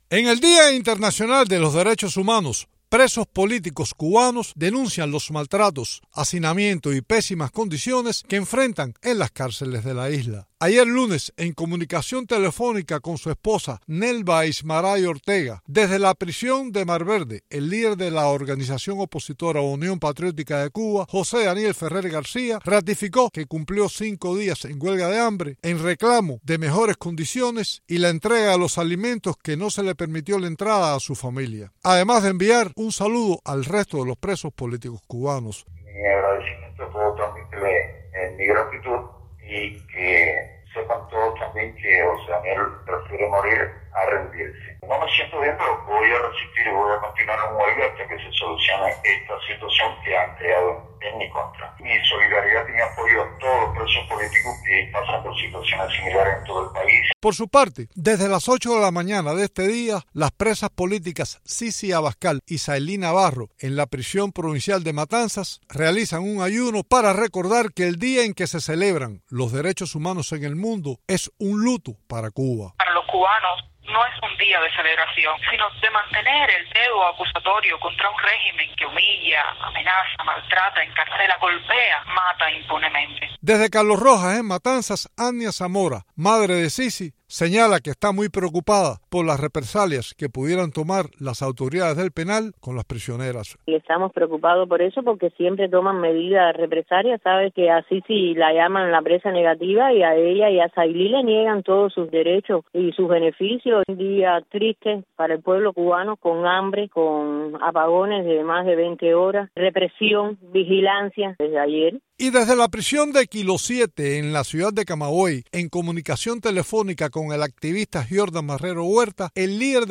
Maltratos, hacinamiento, mala alimentación, insalubridad y escasez de medicamentos, así describen presos políticos cubanos la situación en las cárceles este 10 de diciembre.